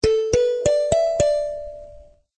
kalimba_short.ogg